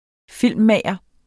Udtale [ -ˌmæˀjʌ ]